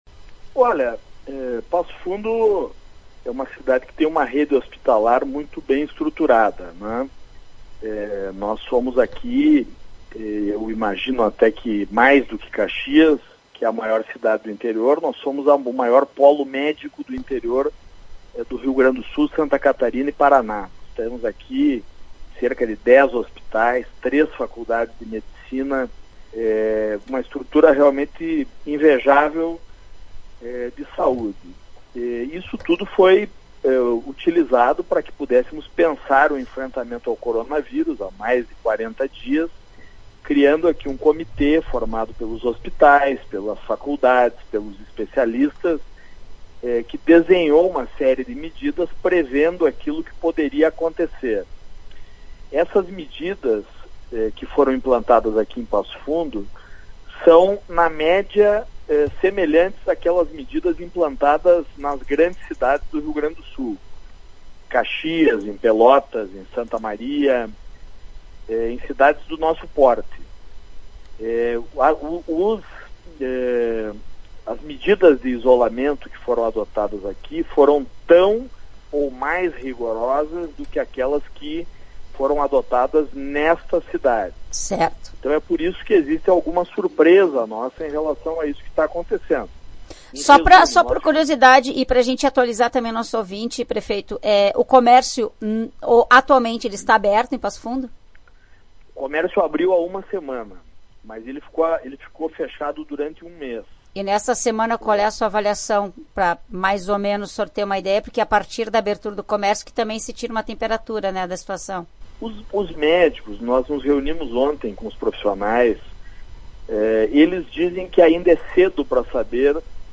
Em entrevista à Tua Rádio São Francisco, o prefeito de Passo Fundo, Luciano Azevedo (PSB), acredita que o grande número de mortes e de infectados possui explicações distintas.